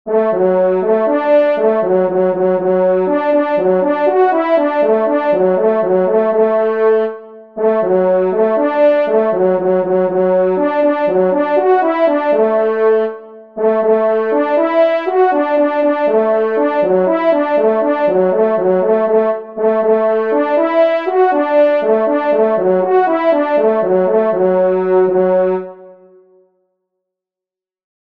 Musique Synthé “French Horns” (Tonalité de Ré